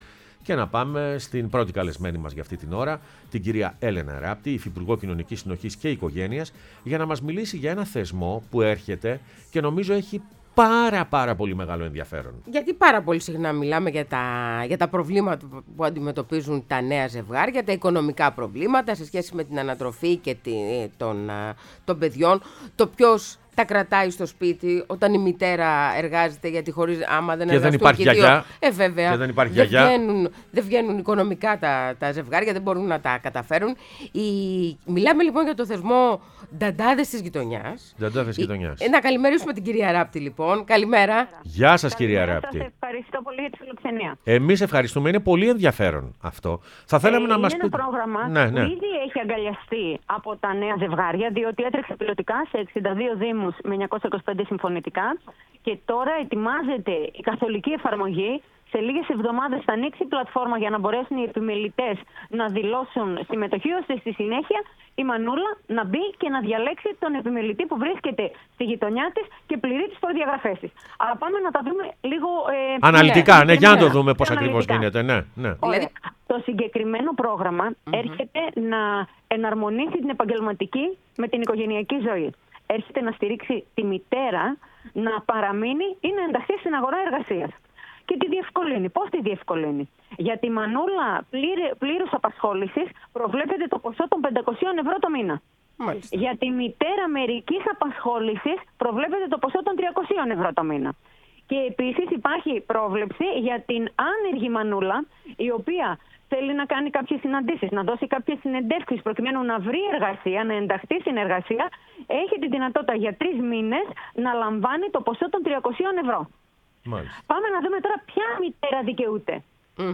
Έλενα Ράπτη, Υφυπουργός Κοινωνικής Συνοχής και Οικογένειας μίλησε στην εκπομπή «Πρωινές Διαδρομές»